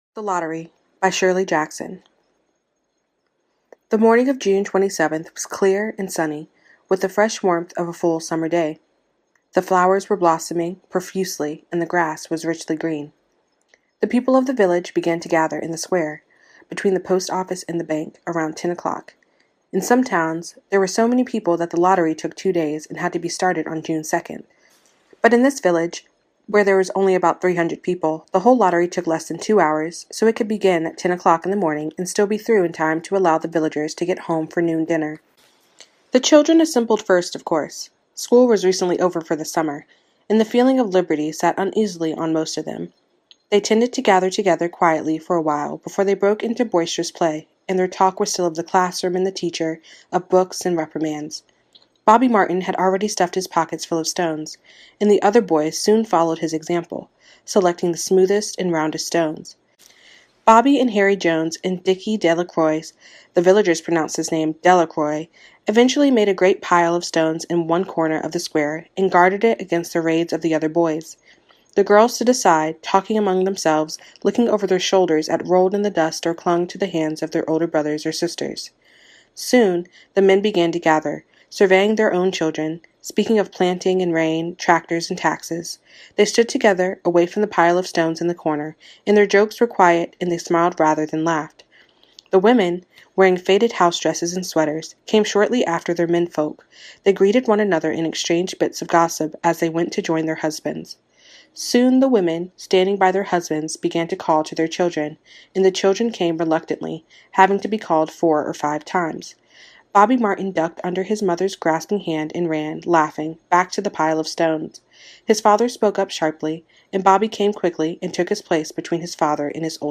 The Lottery by Shirley Jackson – Audio Book (1948)
the-lottery-by-shirley-jackson-audiobook.mp3